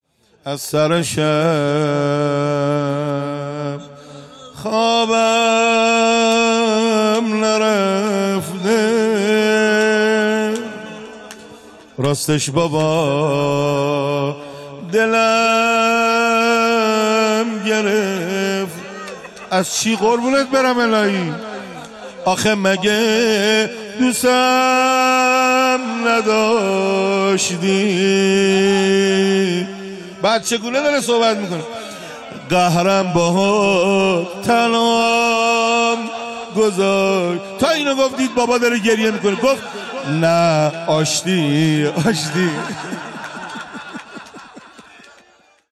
نوحه خوانی | از سر شب خوابم نرفته راستش بابا دلم گرفته